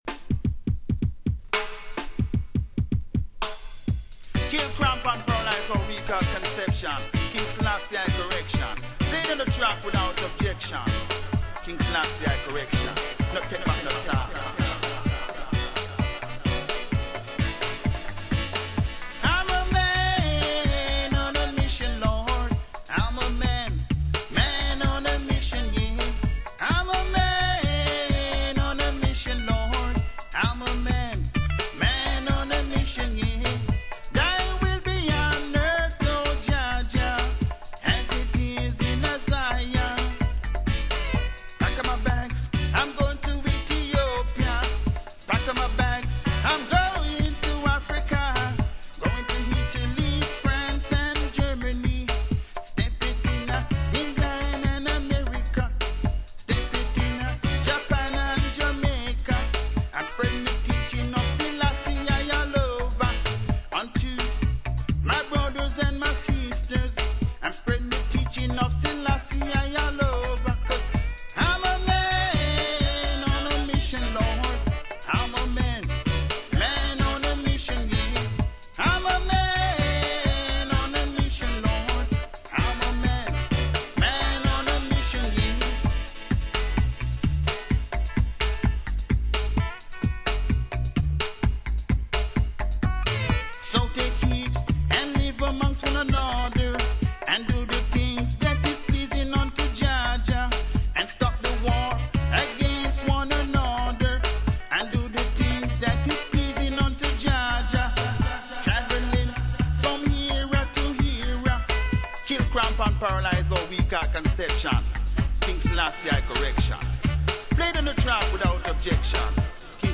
Roots & Culture For all conscious listener.